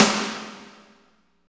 48.06 SNR.wav